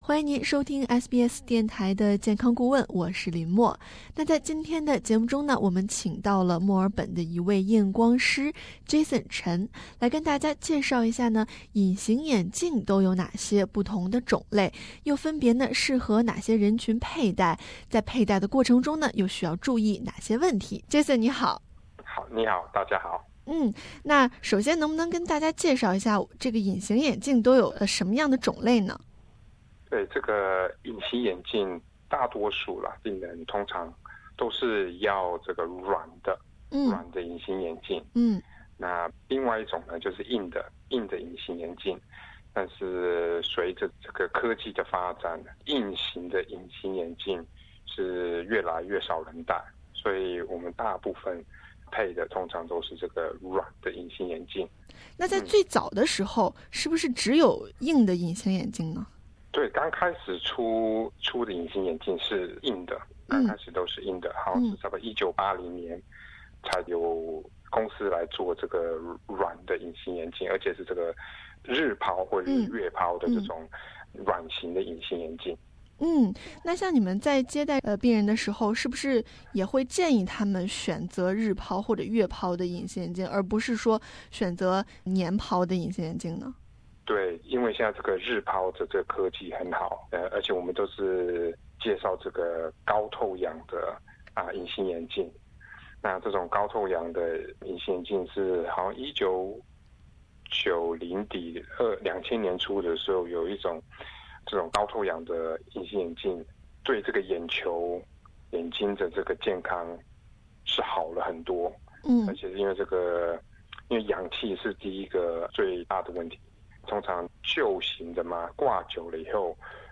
an optometrist in Melbourne